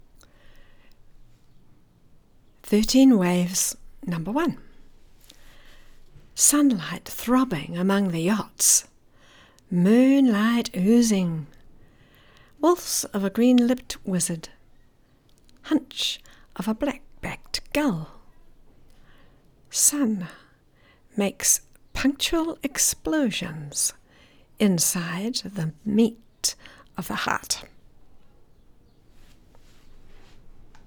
Poem and reading